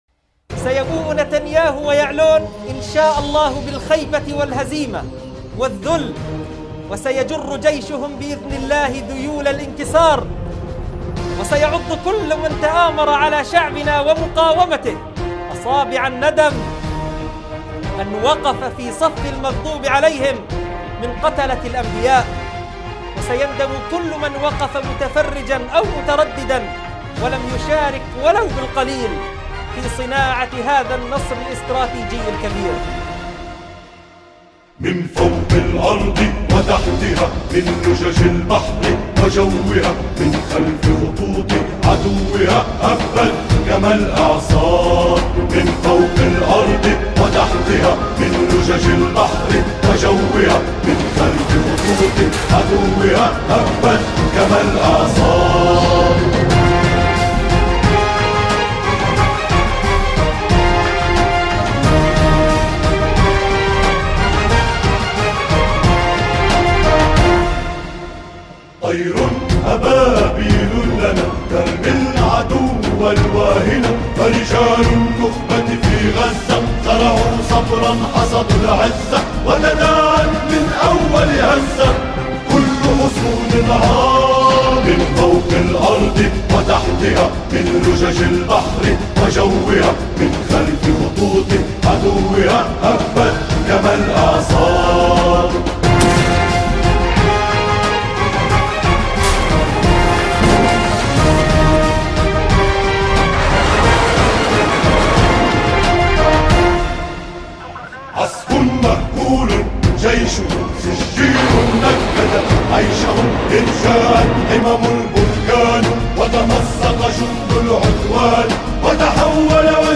أناشيد فلسطينية... من فوق الأرض وتحتها